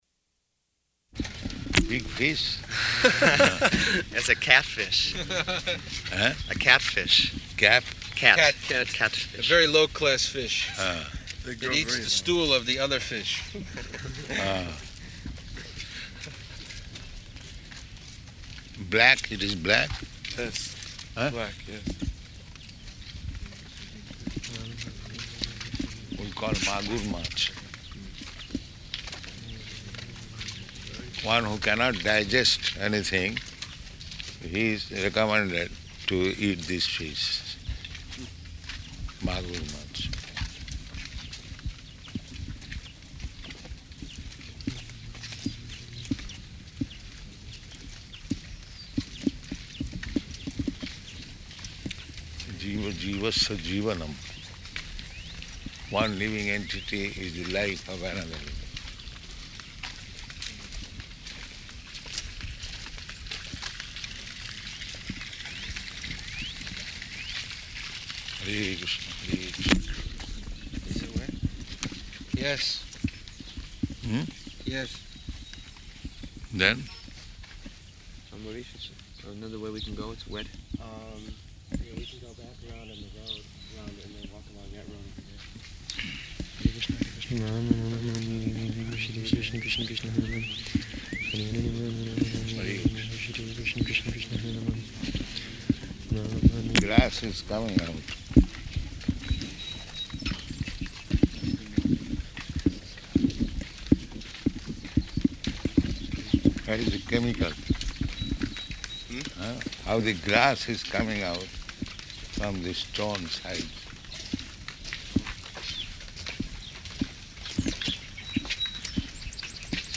Type: Walk
Location: Detroit